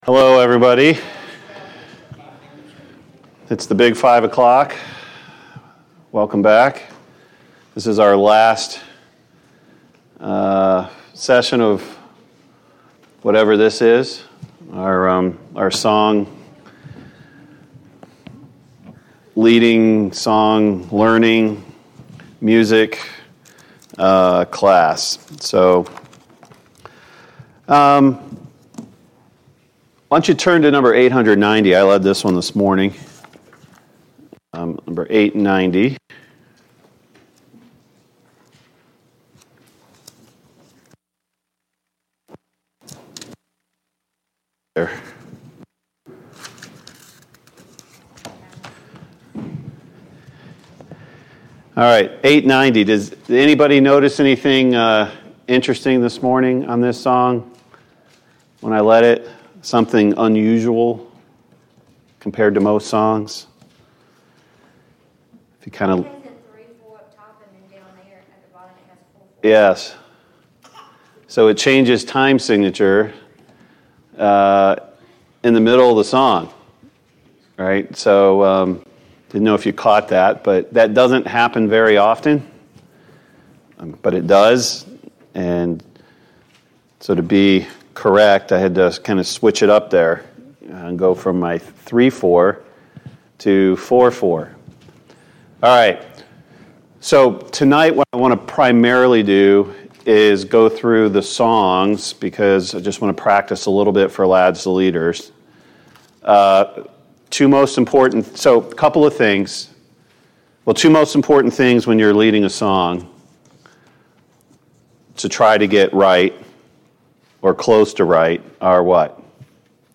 4. Singing Class